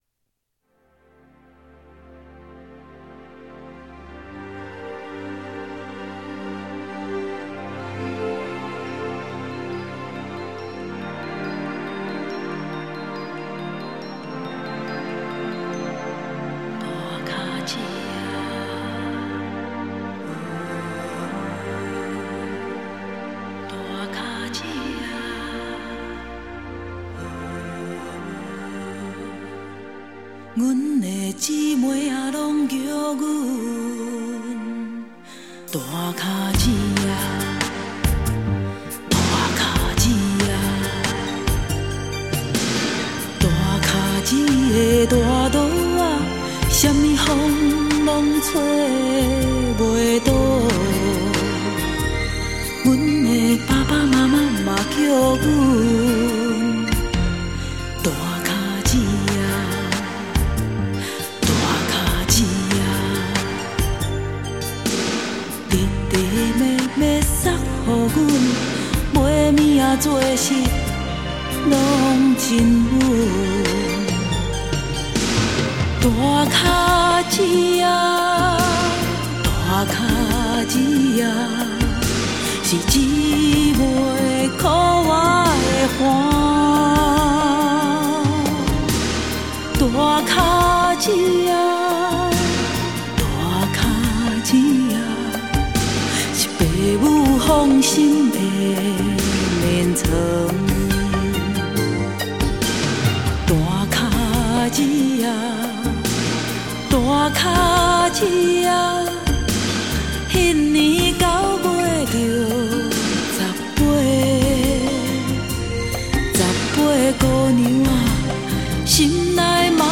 有孤独的自白，有无奈的咏欢，也有伤感的低语
以非常沉静的笔触、平实的曲风